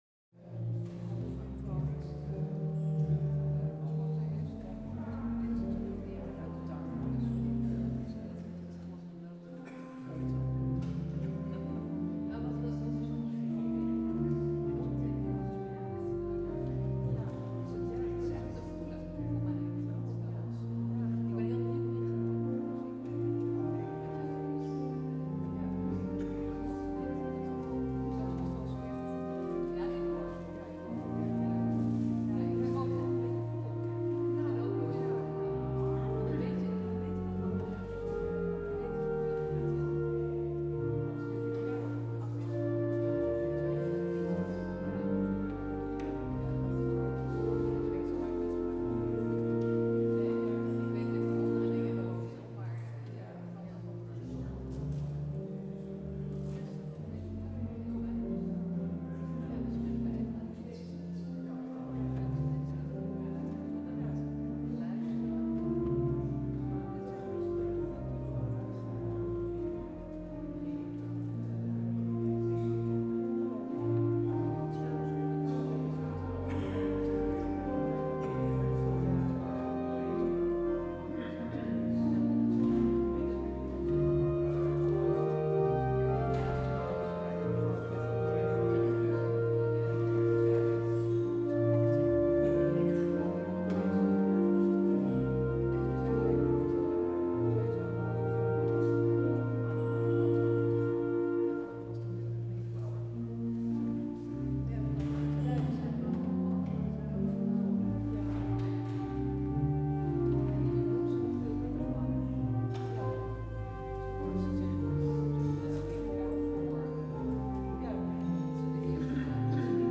 Luister deze kerkdienst hier terug: Alle-Dag-Kerk 10 december 2024 Alle-Dag-Kerk https